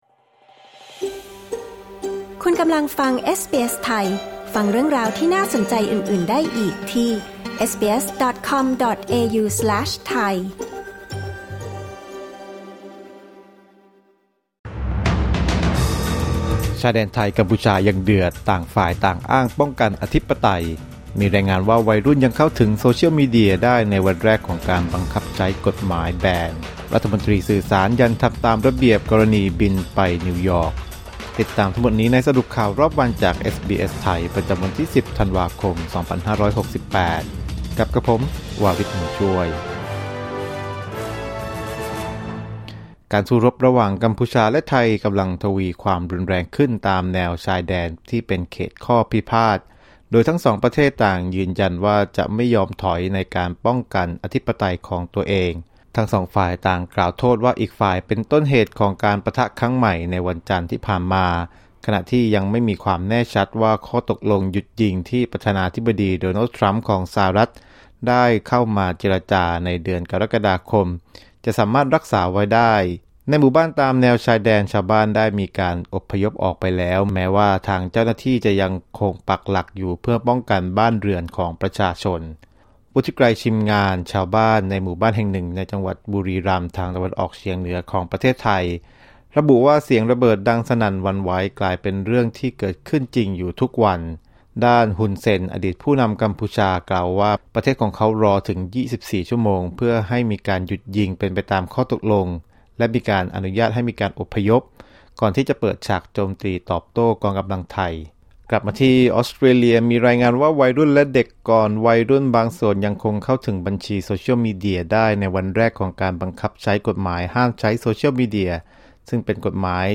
สรุปข่าวรอบวัน 10 ธันวาคม 2568